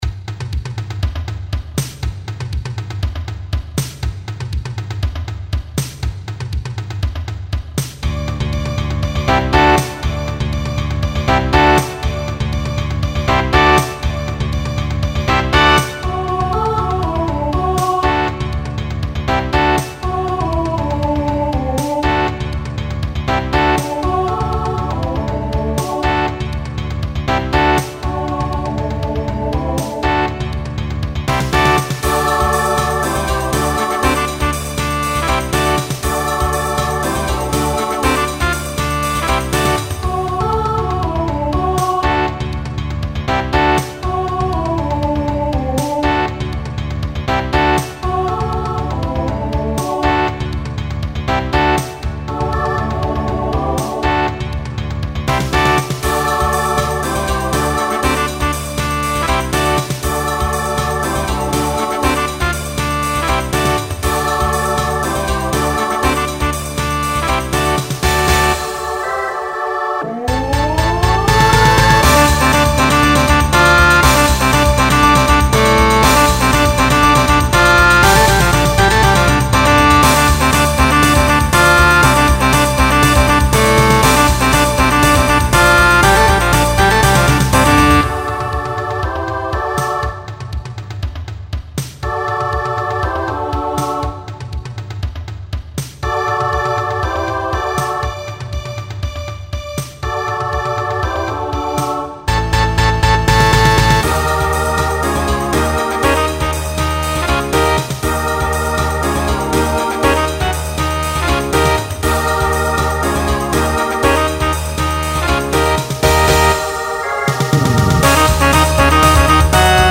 Genre Rock Instrumental combo
Voicing SSA